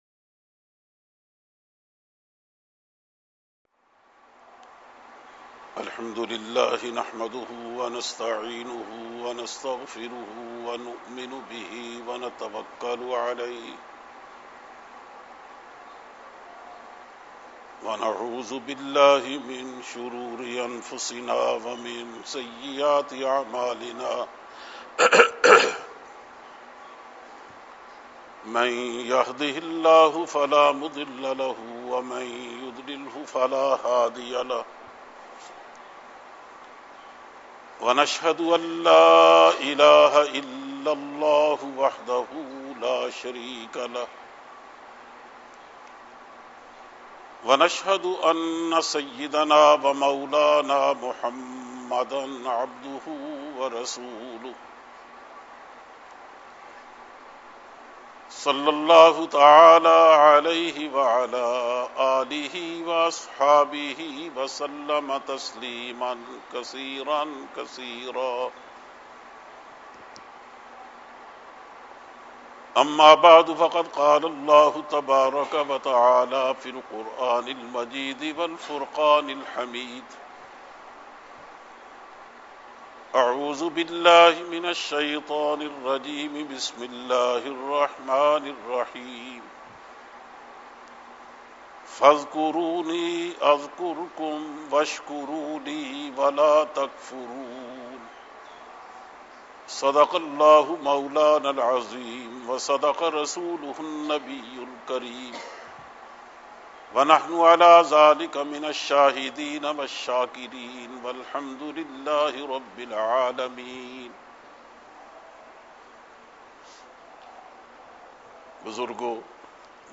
Kutba-e-Juma